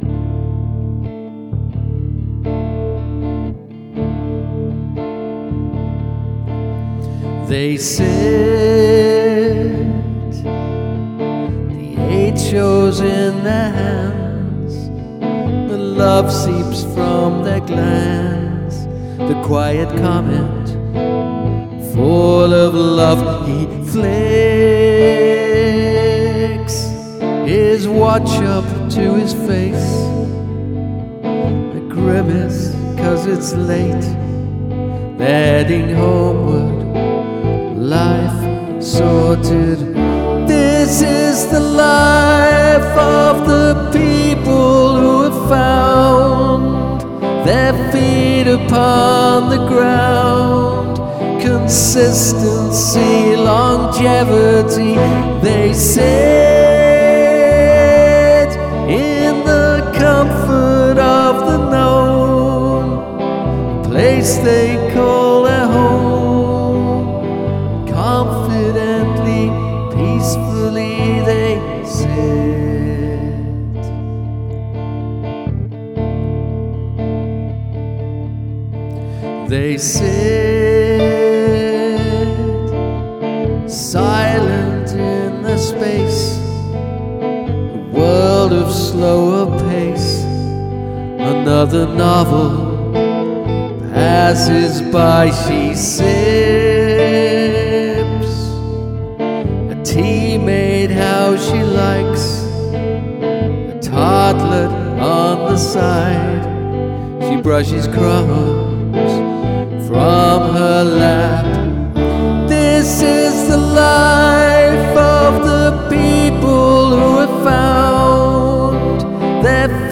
A still song about genuine, aged love.